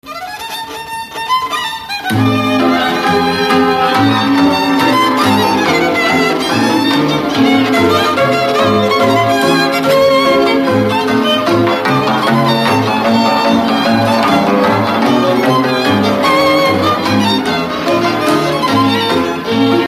Dallampélda: Hangszeres felvétel
Alföld - Szatmár vm. - Rozsály
hegedű
cimbalom
kontra
bőgő
Műfaj: Lassú csárdás
Stílus: 1.1. Ereszkedő kvintváltó pentaton dallamok